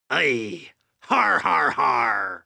VS_fcustomx_haha.wav